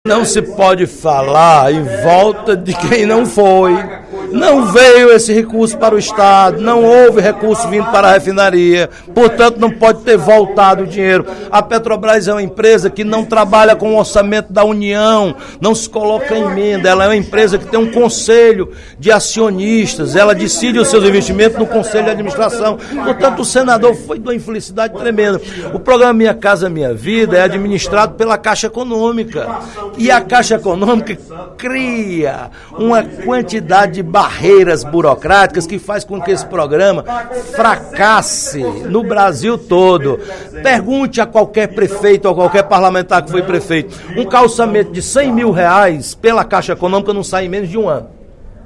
Em pronunciamento na sessão plenária desta quinta-feira (16/02), o deputado Roberto Mesquita (PV) fez duras críticas à Caixa Econômica Federal e à Petrobras que estão, segundo ele, “atrapalhando e atrasando” grandes projetos para o Ceará. Ele citou o programa Minha Casa, Minha Vida e a refinaria como promessas do Governo Federal que até hoje não se concretizaram no Estado.